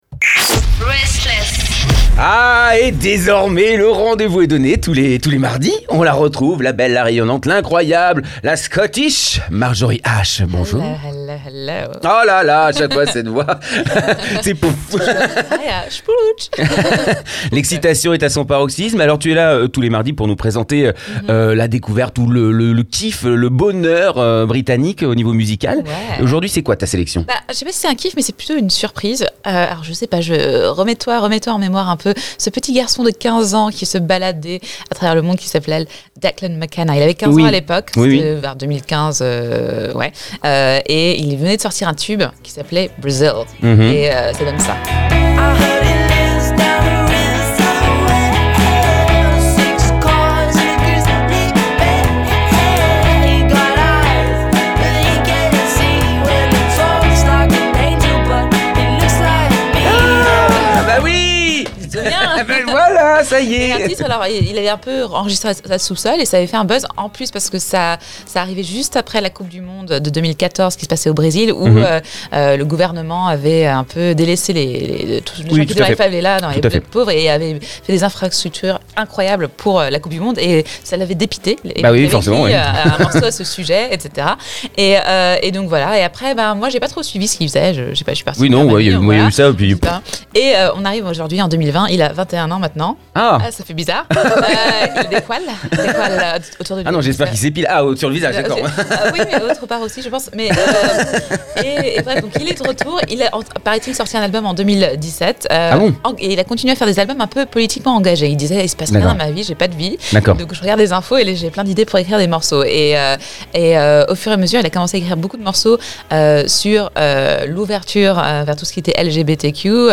Toujours aussi politiquement engagé, il a aujourd’hui mûri et compose des morceaux aux touches un peu plus Glamrock.